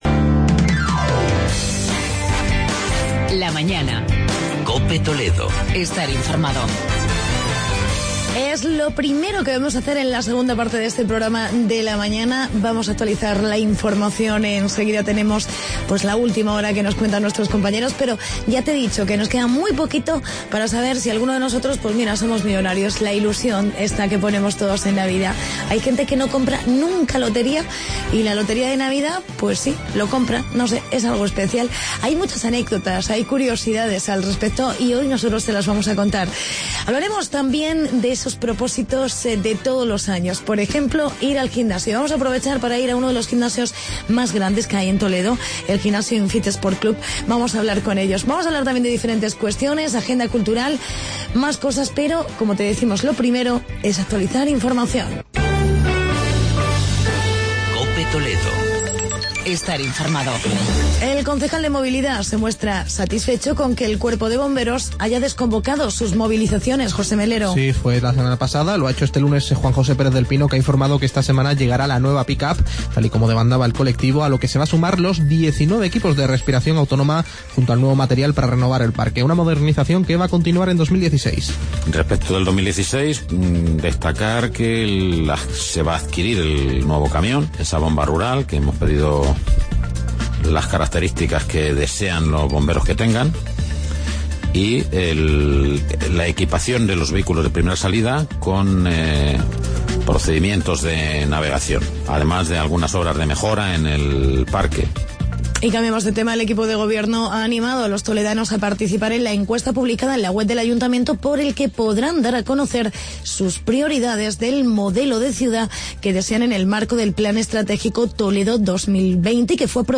Reportajes sobre eventos solidarios en Toledo y Talavera.